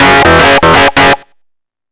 RIFFSYNT03-R.wav